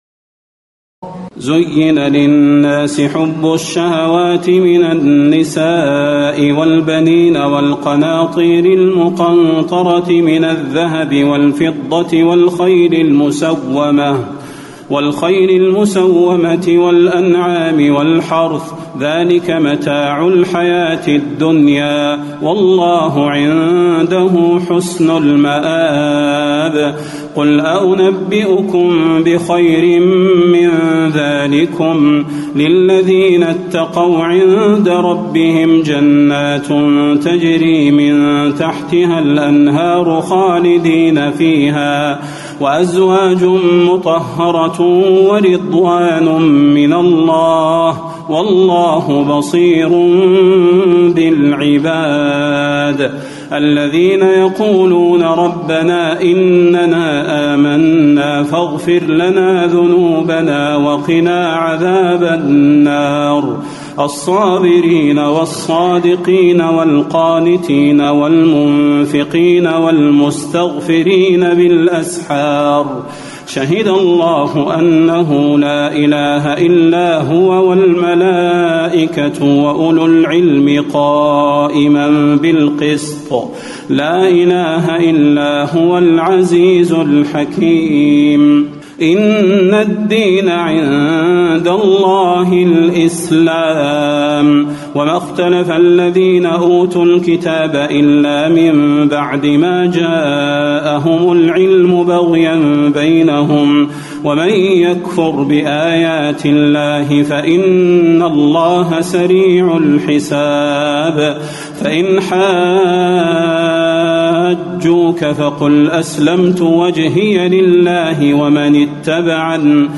تراويح الليلة الثالثة رمضان 1437هـ من سورة آل عمران (14-92) Taraweeh 3st night Ramadan 1437H from Surah Aal-i-Imraan > تراويح الحرم النبوي عام 1437 🕌 > التراويح - تلاوات الحرمين